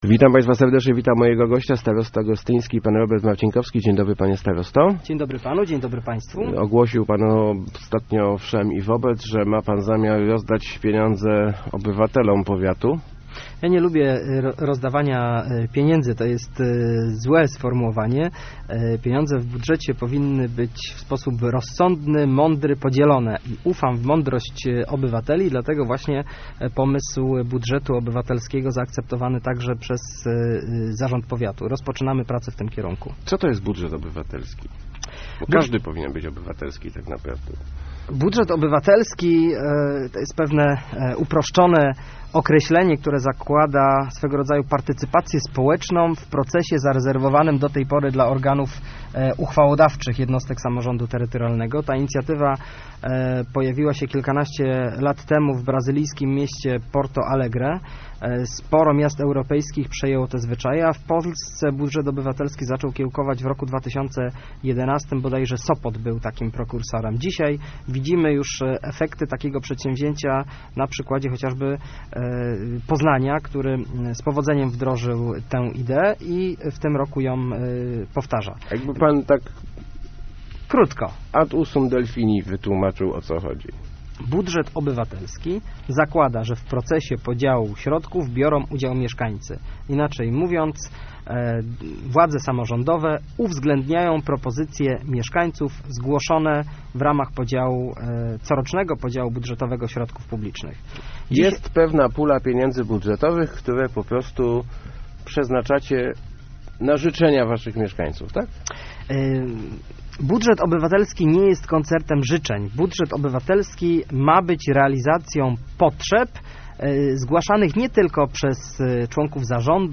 Nie mamy monopolu na mądrość, dlatego chcemy spytać o zdanie mieszkańców - mówił w Rozmowach Elki starosta gostyński Robert Marcinkowski, tłumacząc ideę budżetu obywatelskiego. Samorząd chce przeznaczyć 300 tysięcy złotych na realizację inicjatyw mieszkańców.